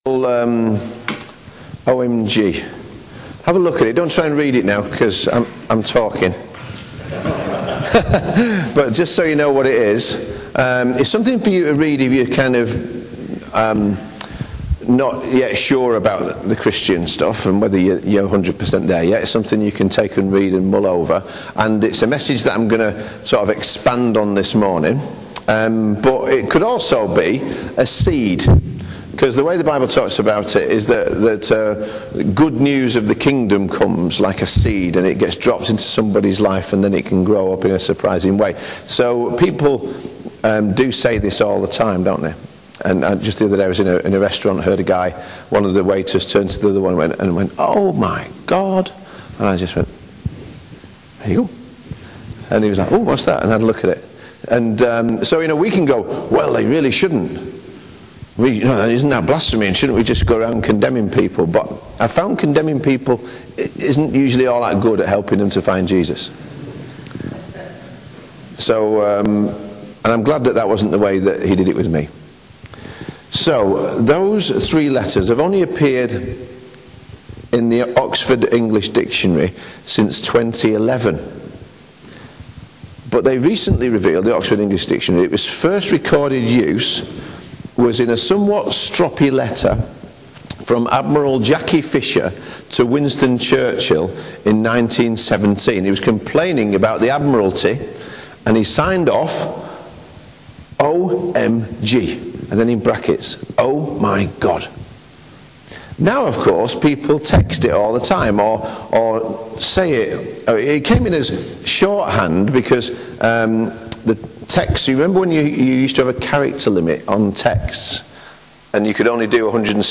Mens-Breakfast-talk-Sat-8-Feb-2014.mp3